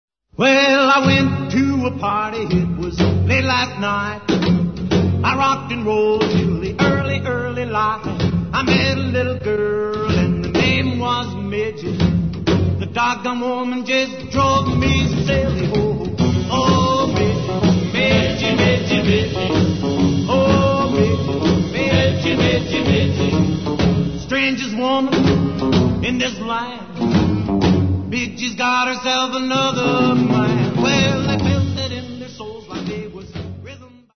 Talents : Vocals, Guitar